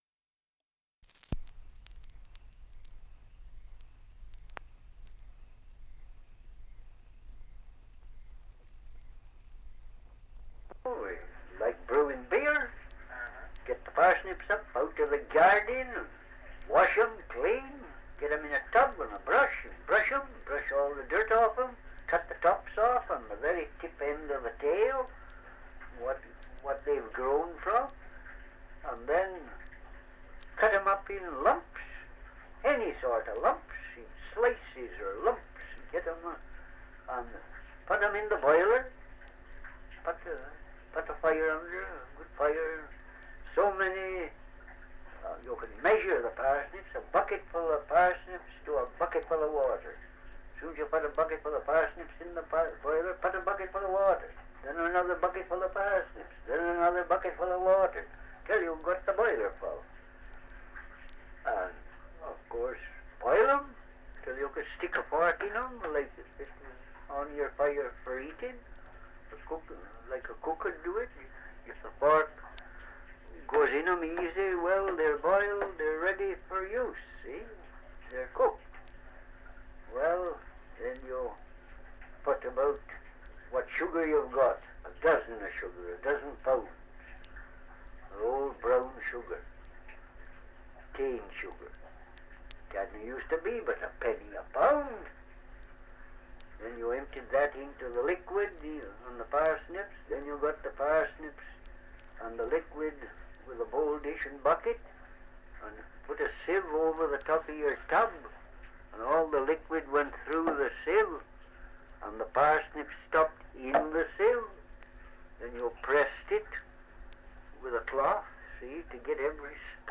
Dialect recording in Longnor, Shropshire
78 r.p.m., cellulose nitrate on aluminium